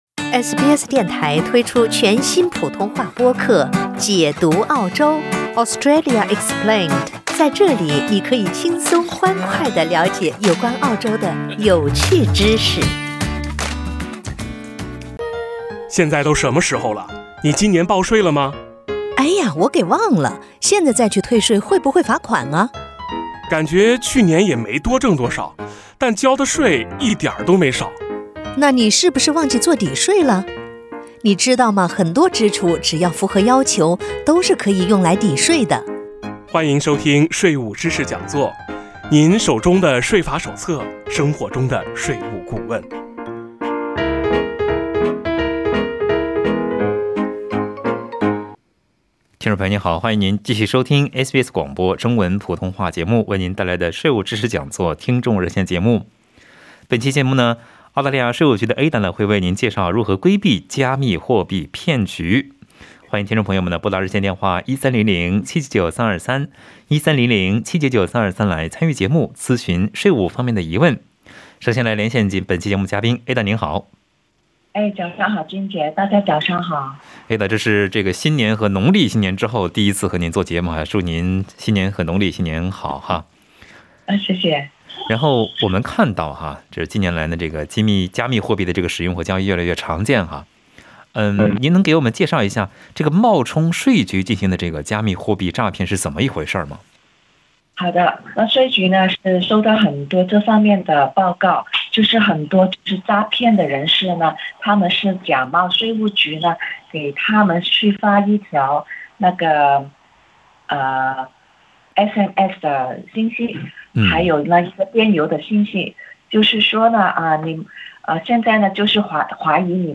SBS 普通话电台 View Podcast Series Follow and Subscribe Apple Podcasts YouTube Spotify Download (51.44MB) Download the SBS Audio app Available on iOS and Android 纳税人如何辨别冒充税务局进行的加密货币诈骗呢？